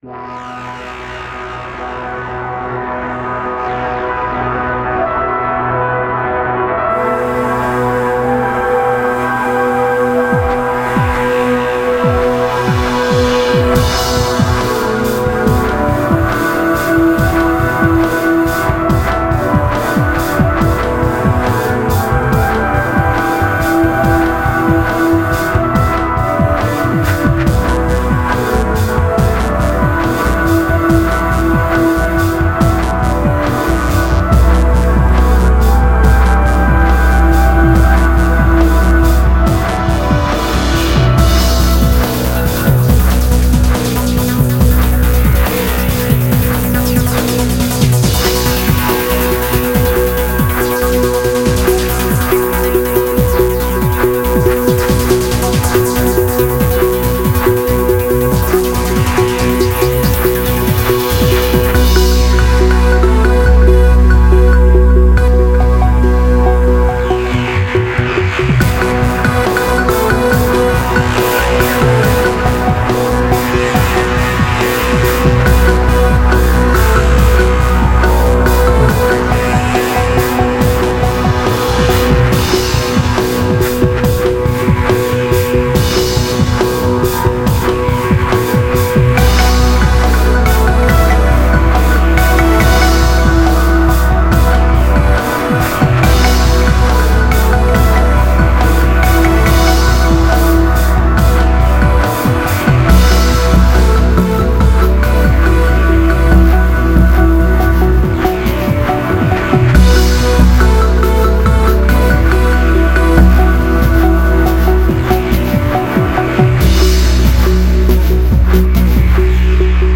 Отличный, мощьно-сталкеровский трек, "живые" ударники.